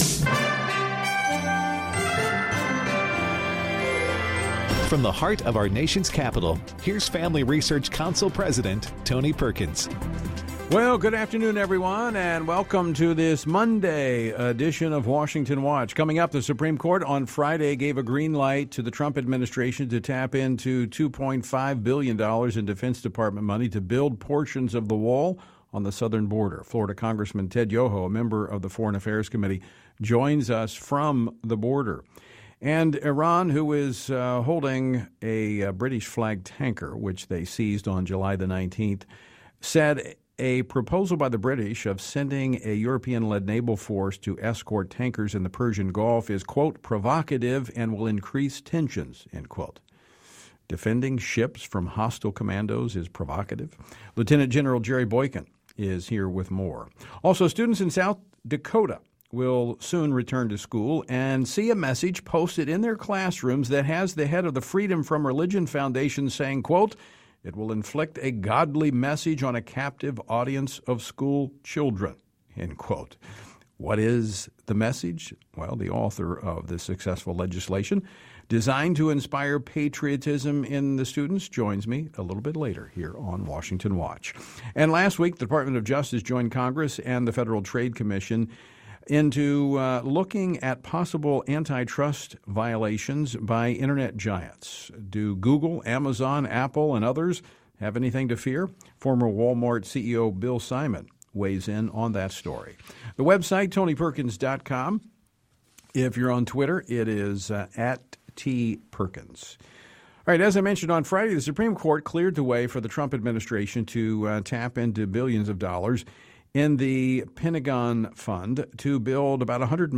Ted Yoho , U.S. Representative for the 3 rd District of Florida, to give a live update from the U.S.-Mexico border and discuss the border wall’s latest win at the Supreme Court.